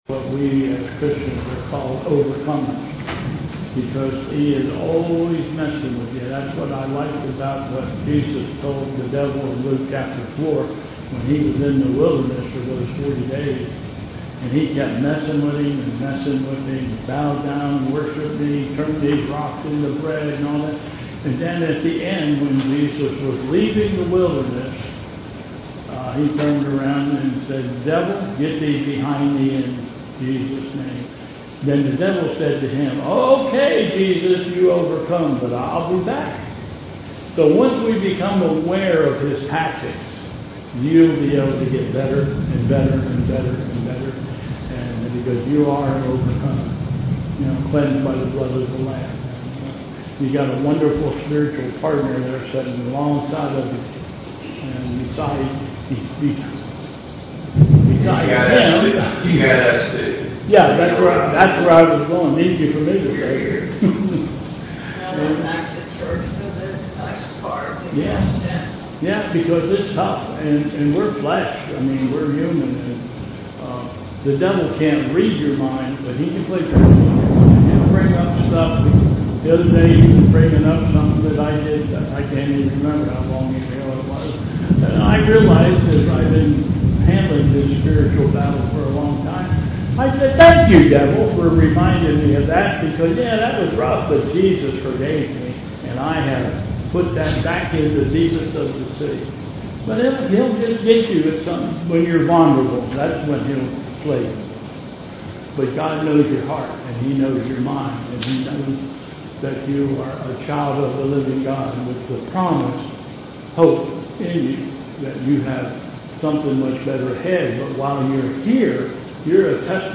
Bethel Church Service
You will hear the thunder from the storm in the background